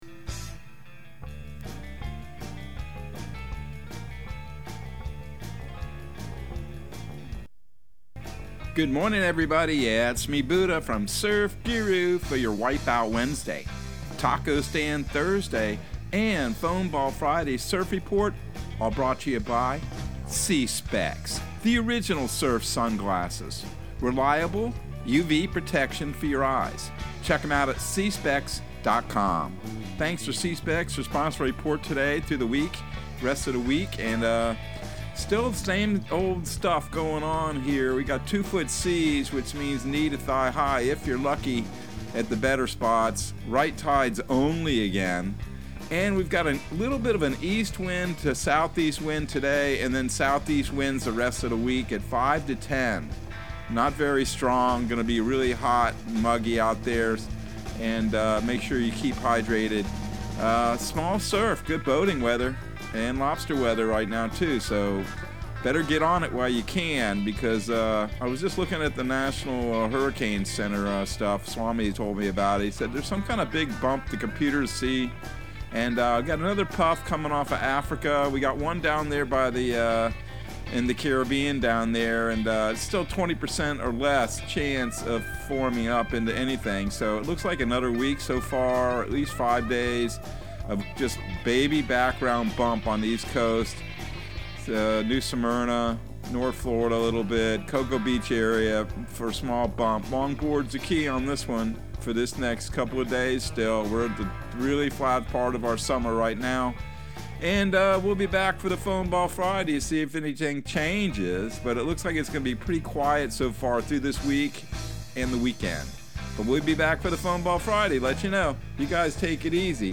Surf Guru Surf Report and Forecast 08/24/2022 Audio surf report and surf forecast on August 24 for Central Florida and the Southeast.